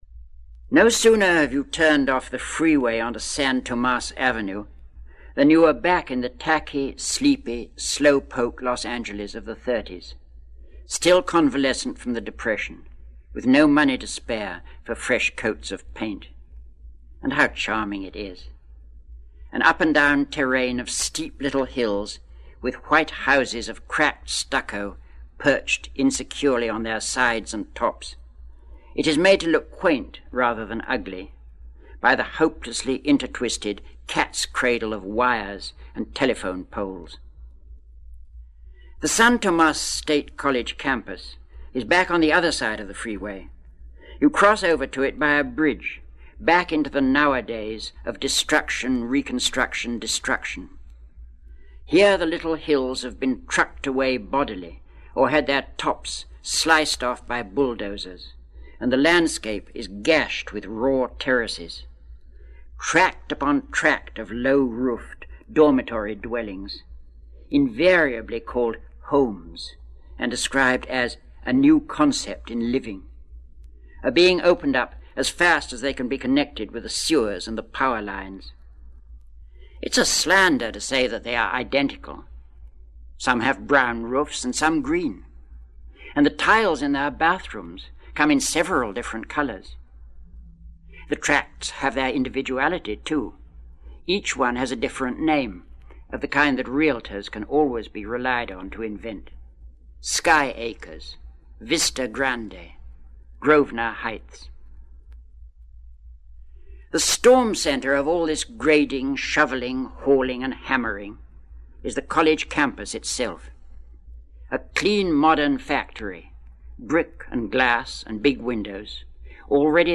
Christopher Isherwood Reads